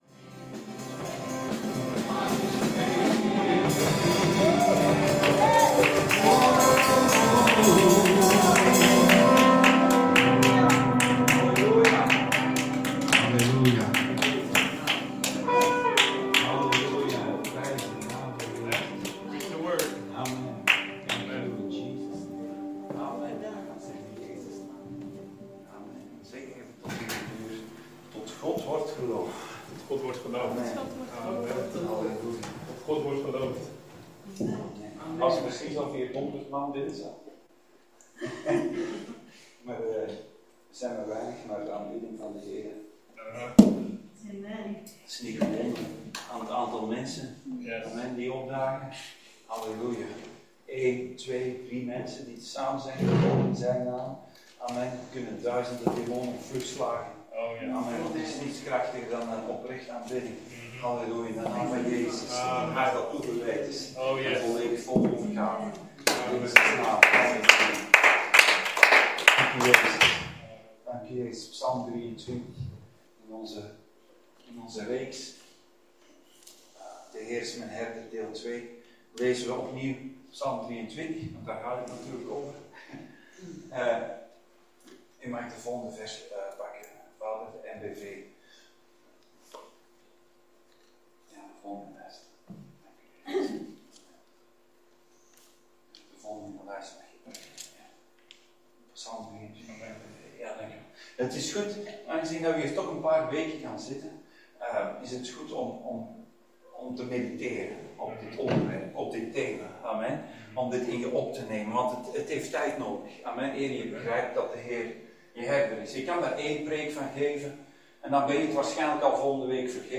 Dienstsoort: Zondag Dienst